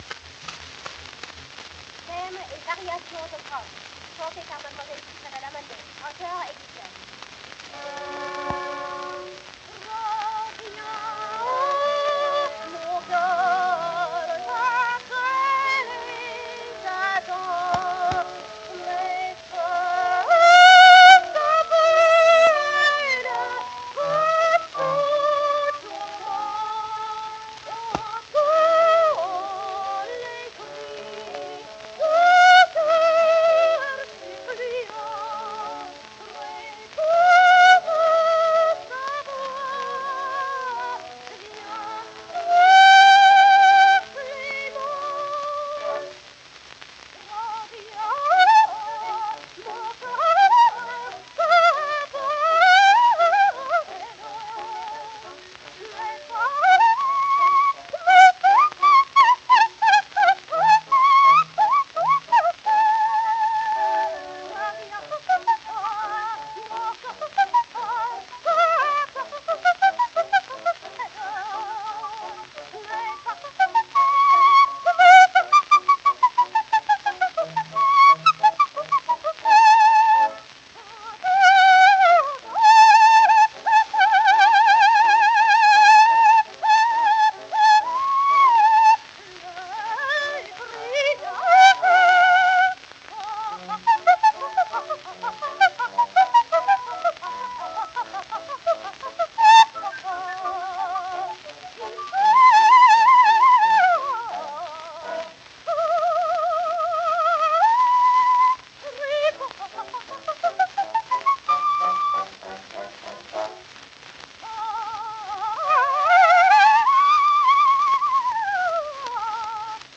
Sopran mit Bläserbegleitung.